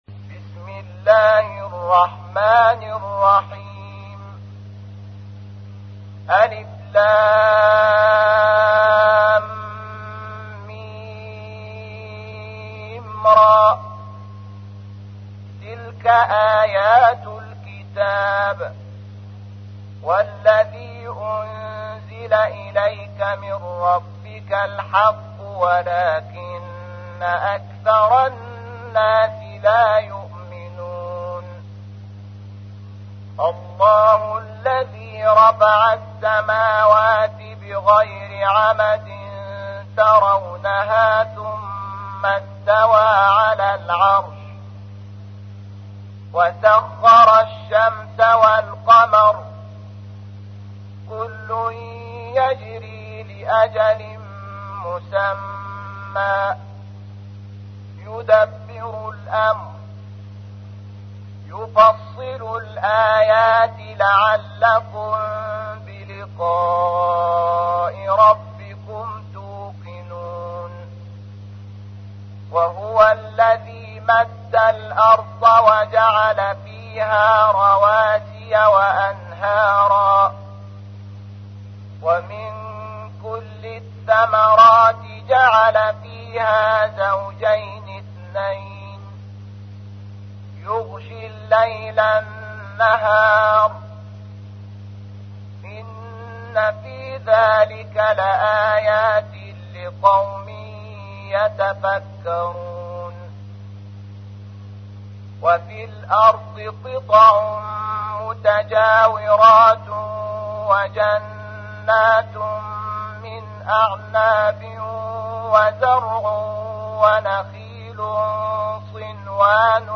تحميل : 13. سورة الرعد / القارئ شحات محمد انور / القرآن الكريم / موقع يا حسين